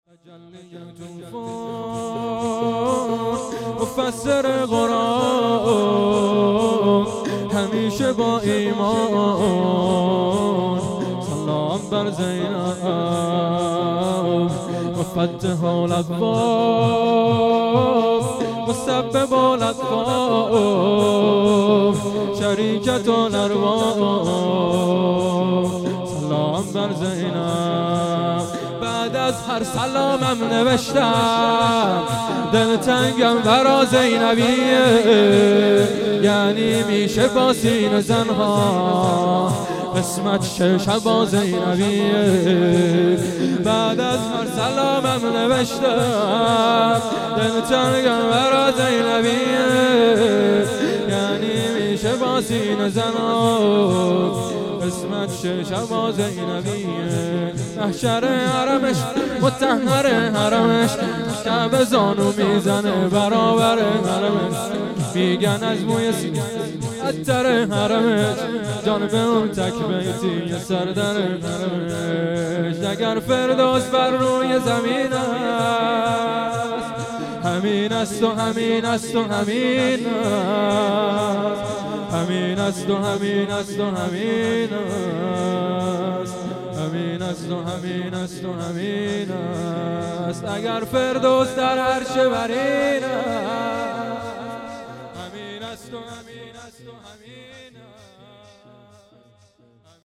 مراسم هفتگی 28 آذرماه 1399
شور هیئت ارباب حسین(ع